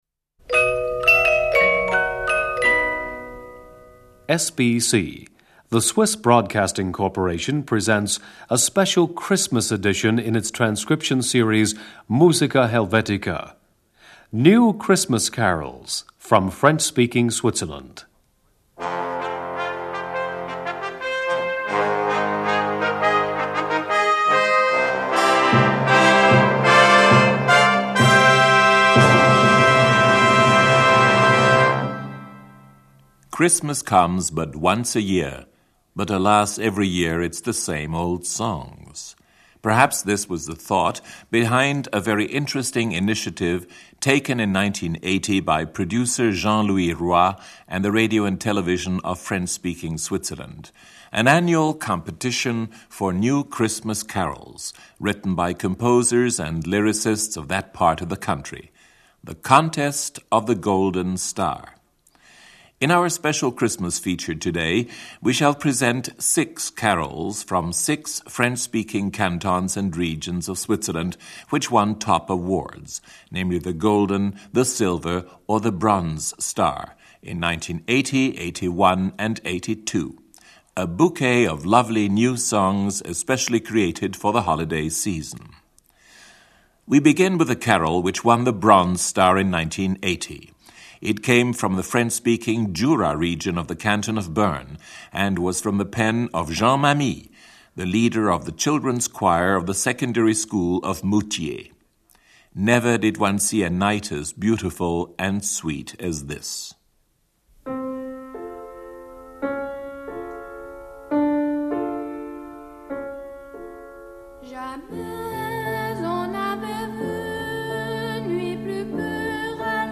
Mixed Choir